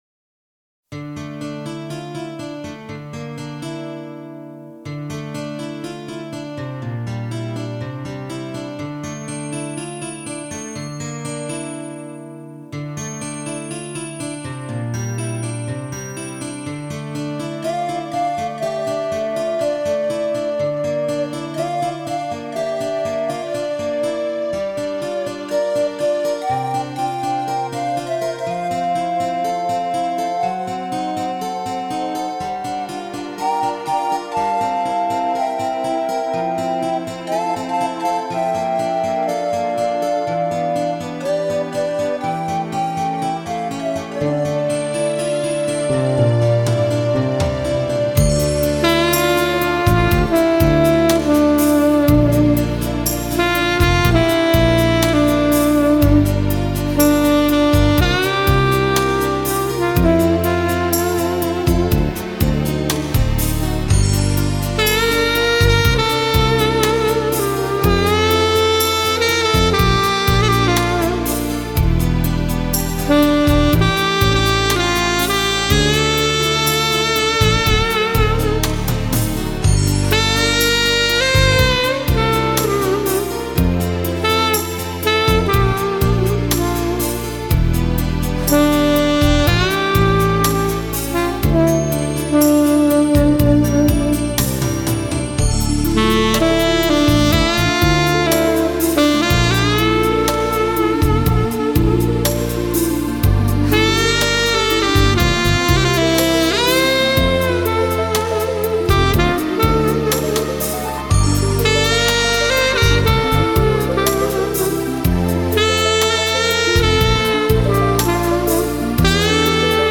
В джазовом варианте (инструментал)